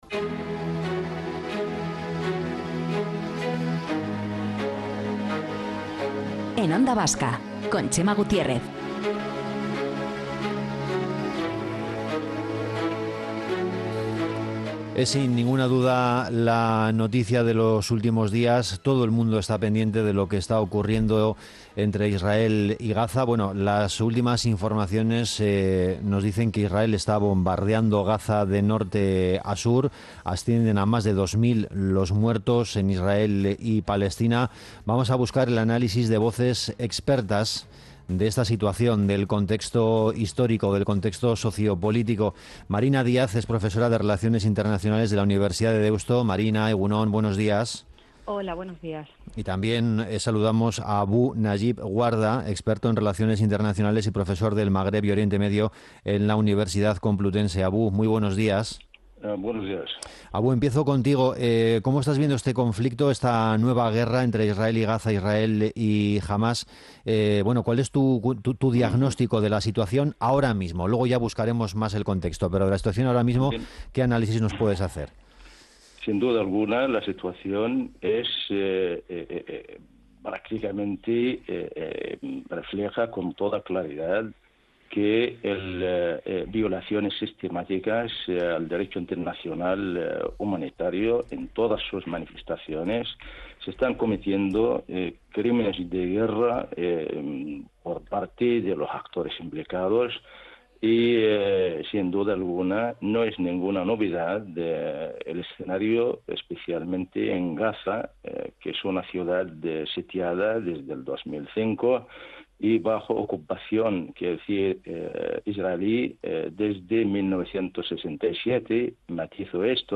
Morning show conectado a la calle y omnipresente en la red.